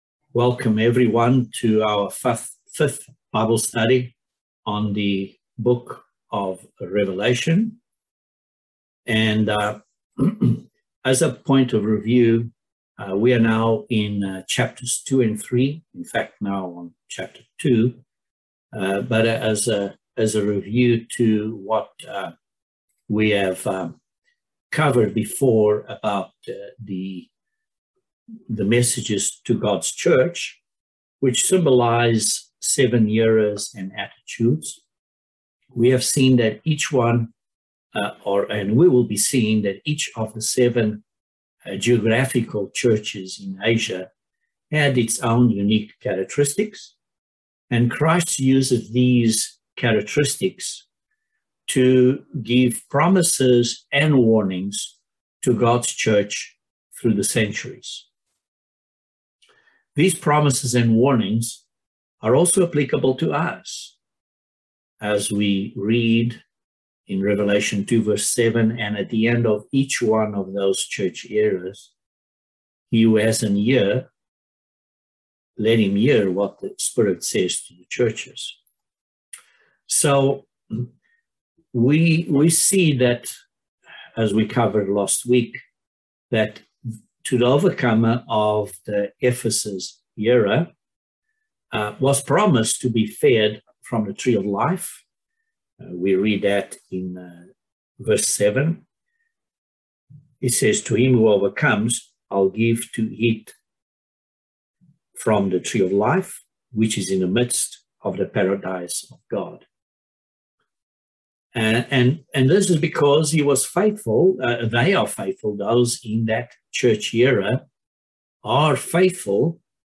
Bible Study No 5 of Revelation